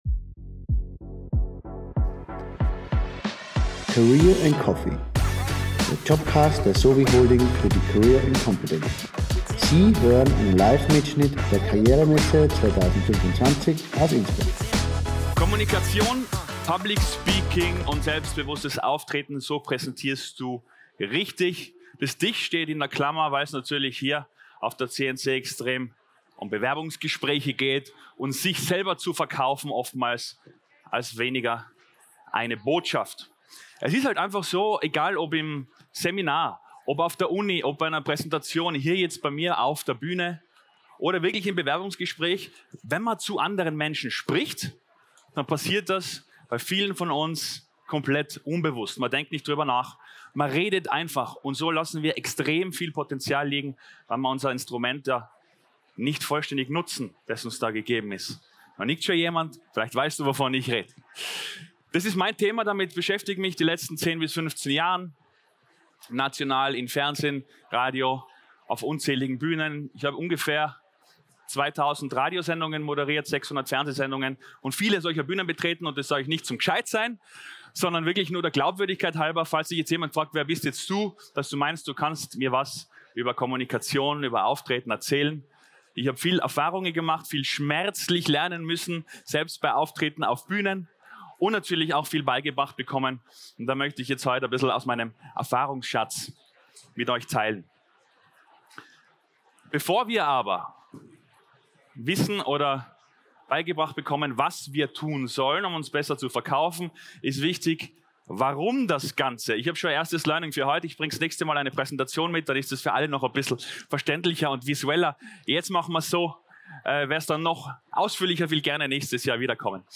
Livemitschnitt #3 von der career & competence am 14. Mai 2025 im Congress Innsbruck.